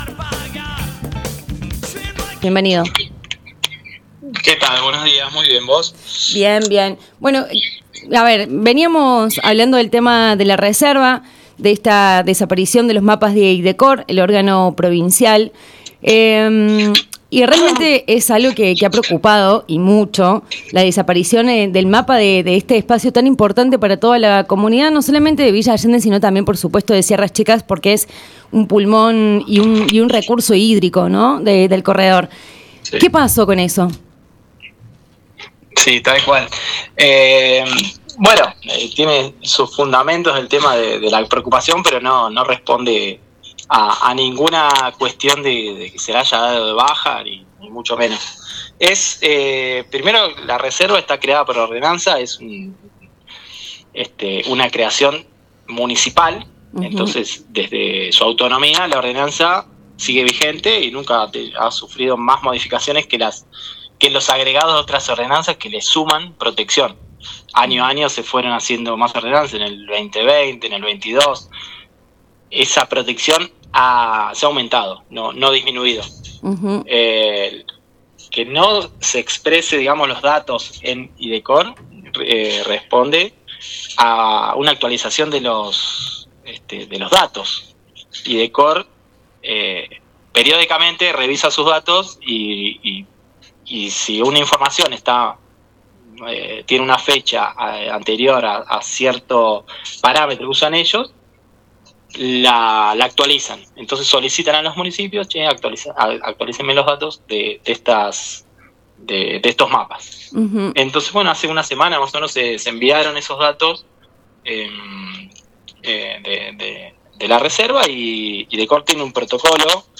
En diálogo con Anexados (lunes a viernes de 9 a 12 hs)